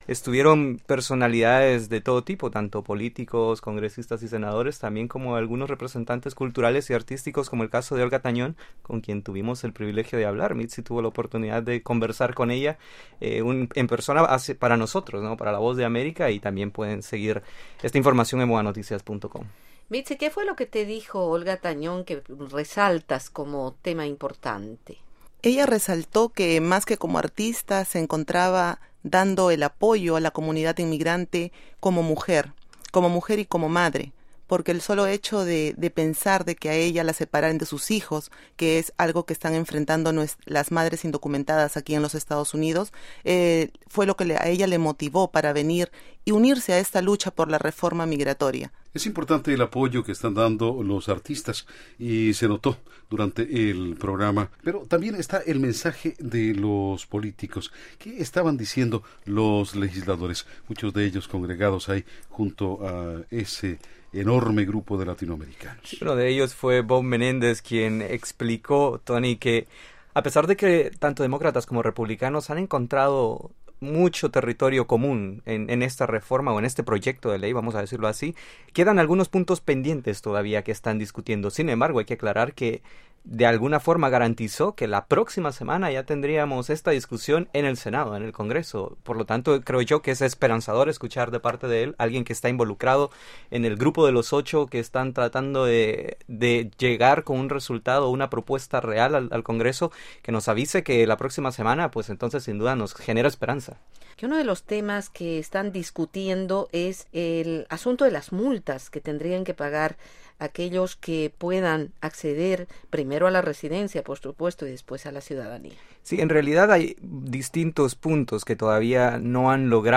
Entrevista en la VOA sobre concentración en Washington